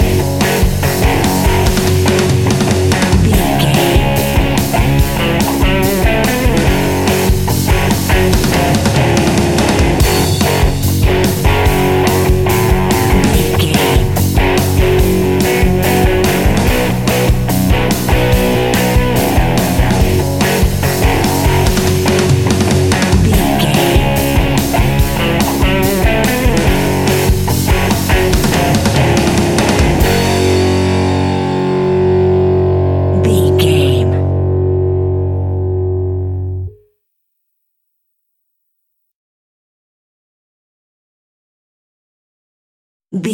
Epic / Action
Fast paced
Ionian/Major
F#
hard rock
blues rock
instrumentals
Rock Bass
heavy drums
distorted guitars
hammond organ